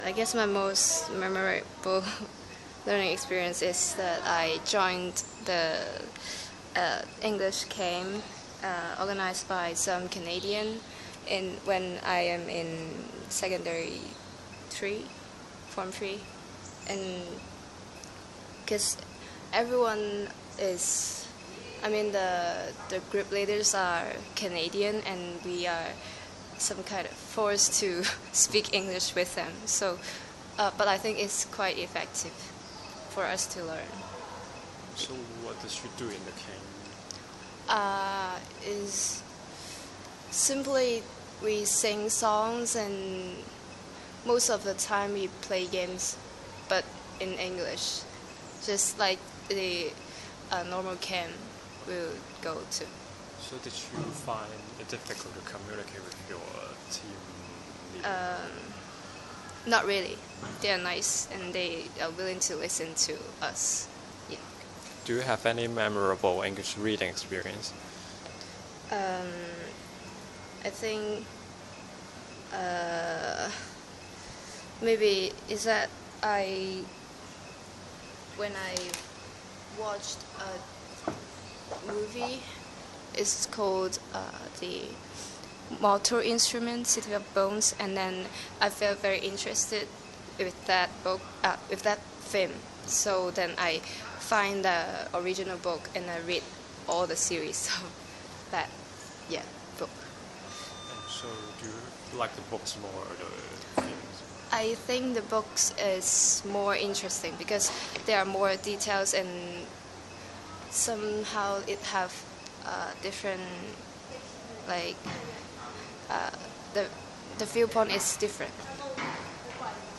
Subcategory: Activity, Fiction, Pop Culture, Reading, Speech